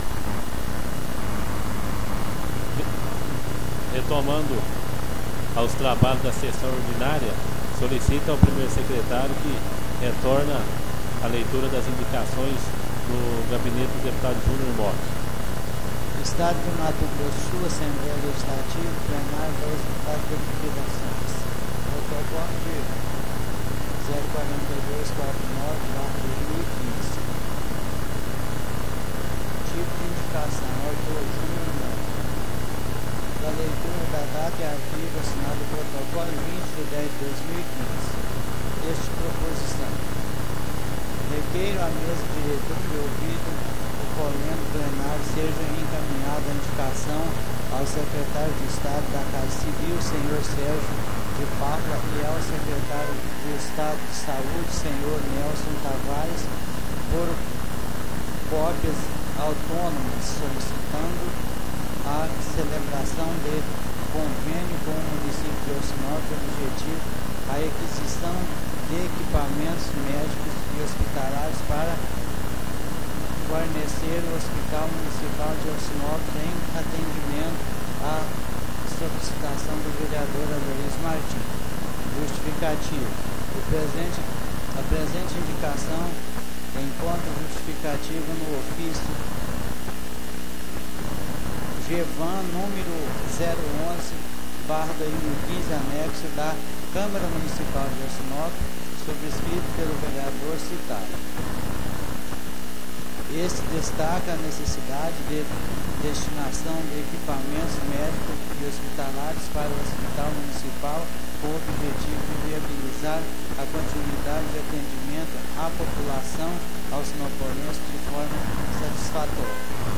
Áudio Sessão Ordinária Nº 36 (5ª parte)